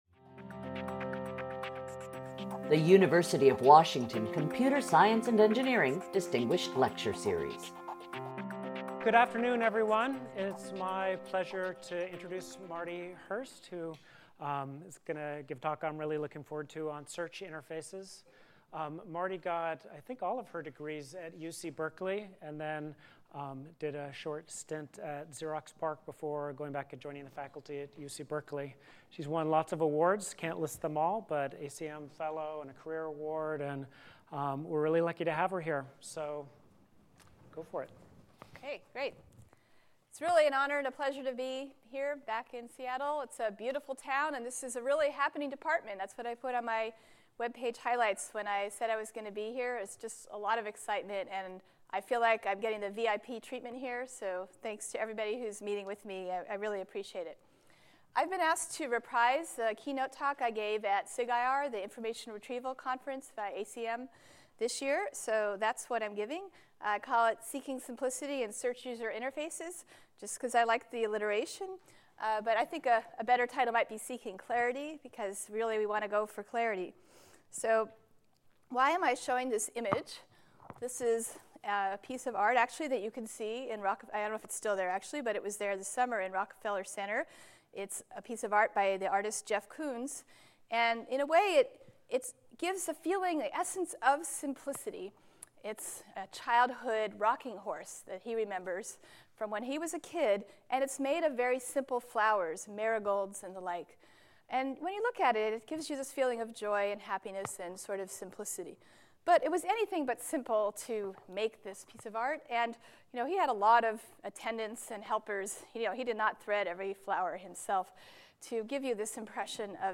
CSE Distinguished Lecture Series